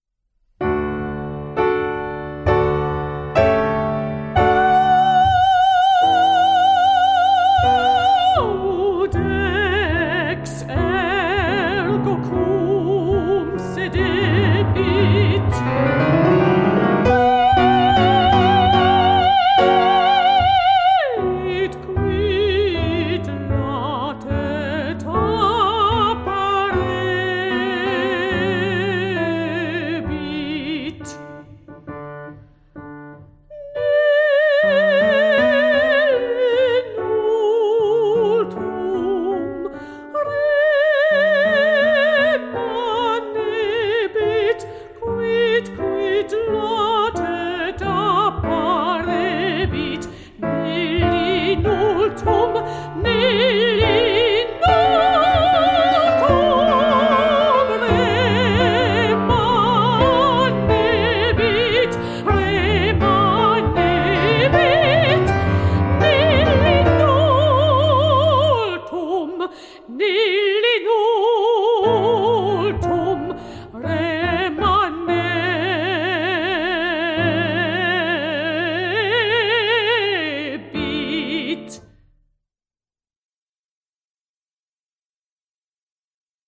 Mezzo Soprano
piano